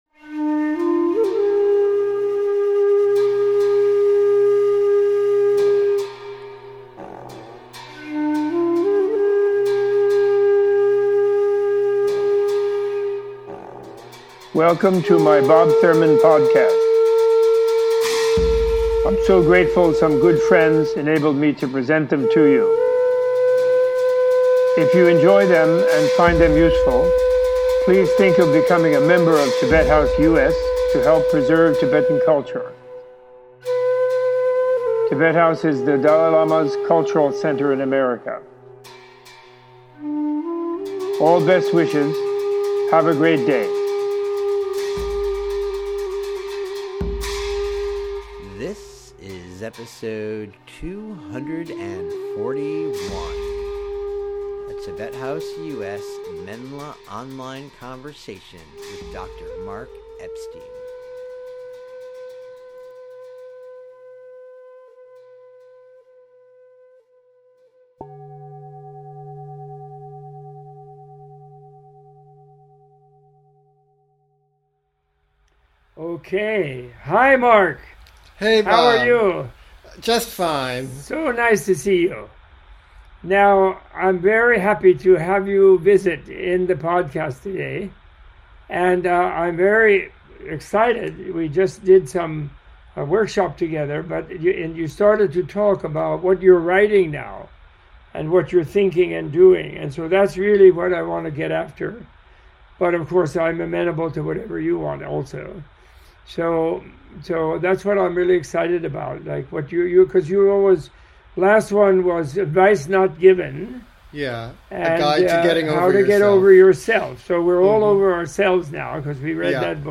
In this extended conversation Robert A.F. Thurman and Dr. Mark Epstein M.D. discuss the history of scientific studies on the effects of meditation training since Buddhism's migration to the West and encounter with materialists, psychotherapists and poets.